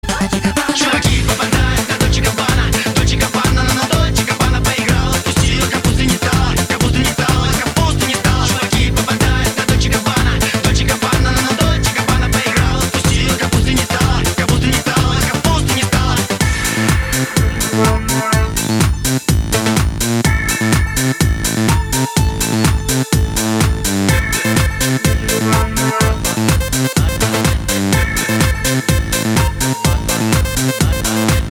• Качество: 192, Stereo
громкие
зажигательные
Club House
качающие
electro house
Классный клубный трек